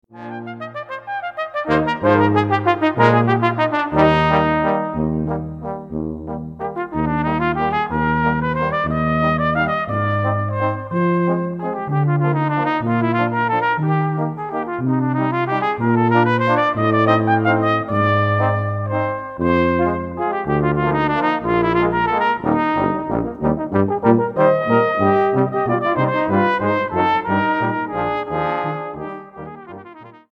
40 - Copier - Ensemble de cuivres Epsilon